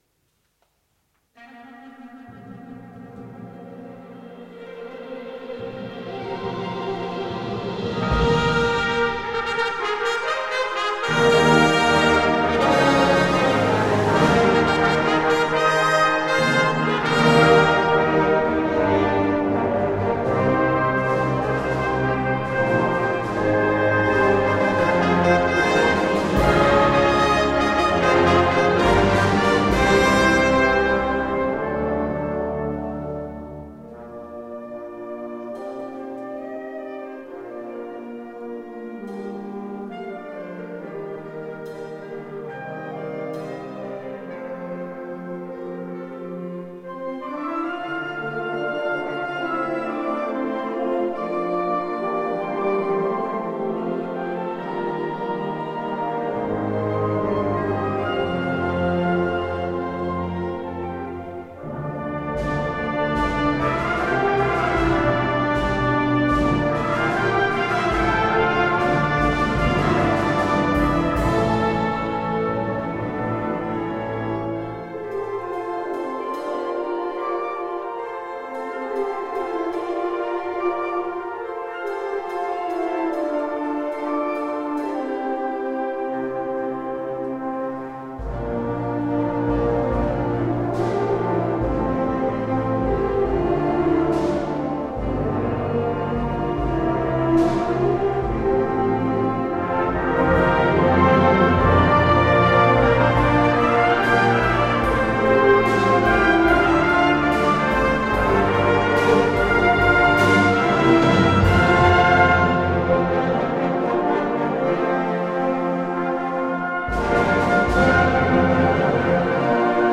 Kirchenkonzert 2011